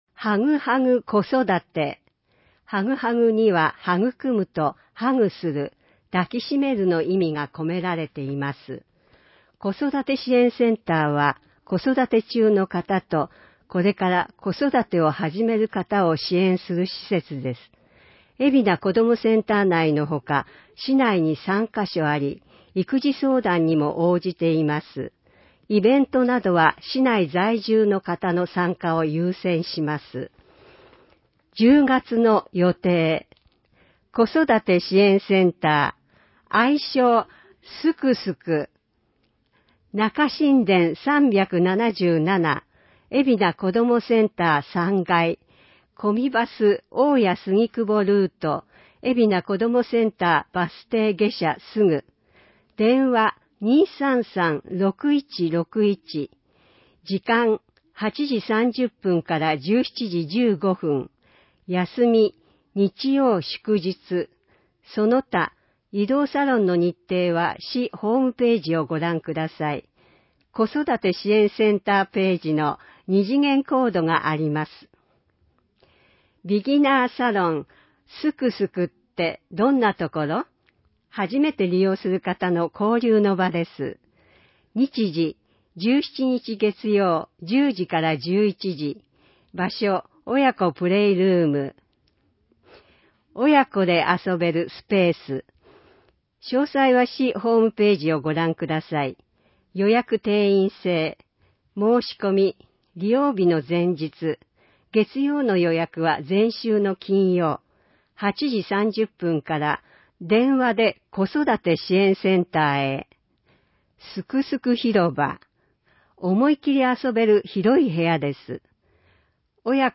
広報えびな 令和4年9月15日号（電子ブック） （外部リンク） PDF・音声版 ※音声版は、音声訳ボランティア「矢ぐるまの会」の協力により、同会が視覚障がい者の方のために作成したものを登載しています。